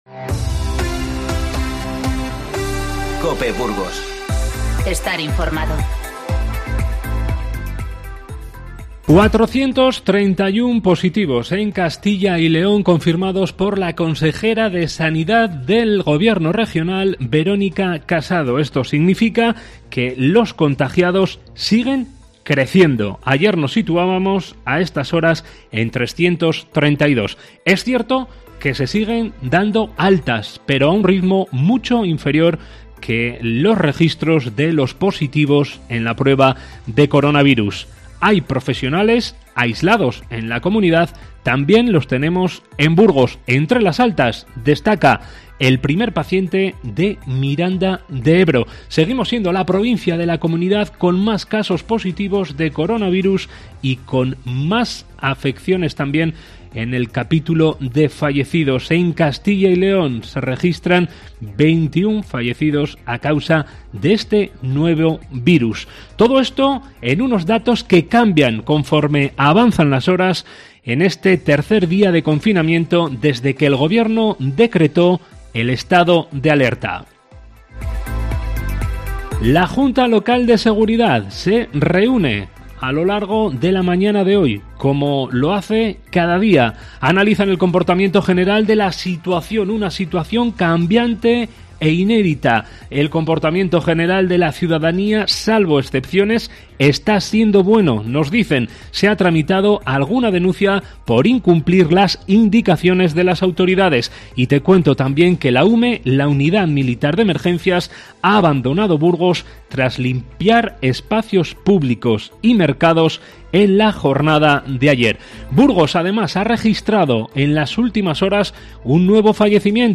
Informativo 17-03-20